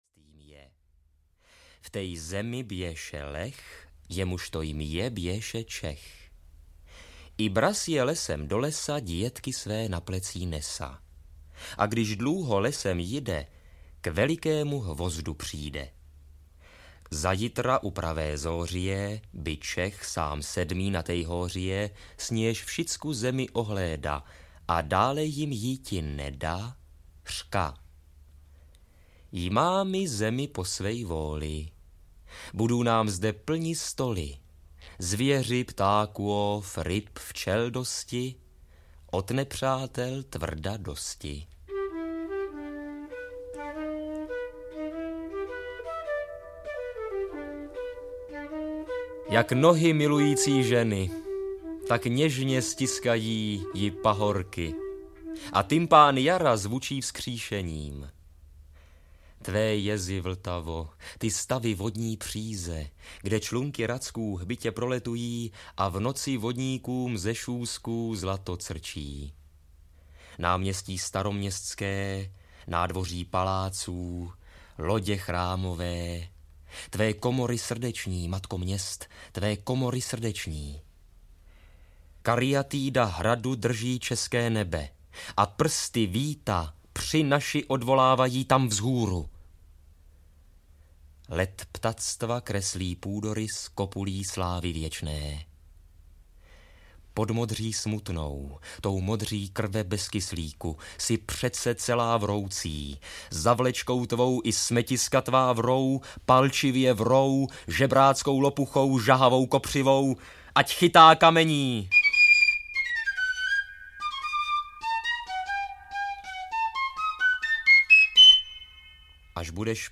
Celostátní přehlídka uměleckého přednesu Neumannovy Poděbrady vycházela od roku 1974 na deskách Supraphonu.